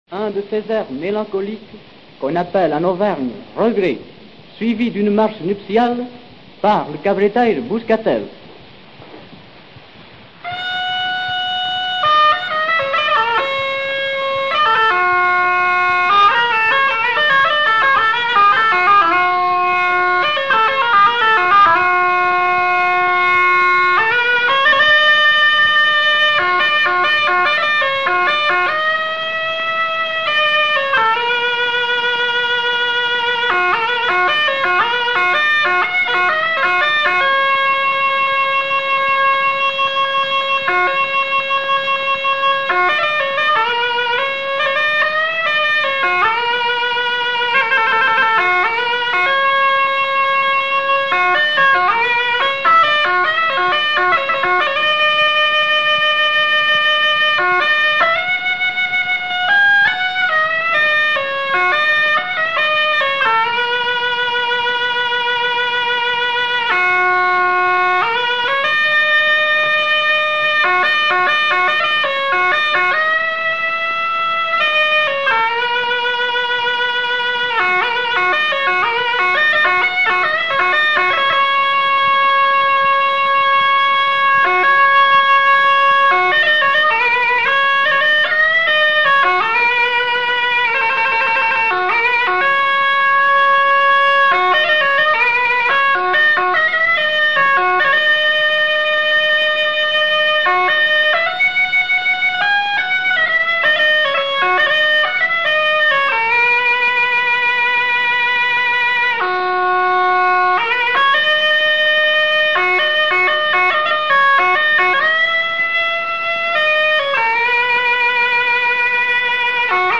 Mélodie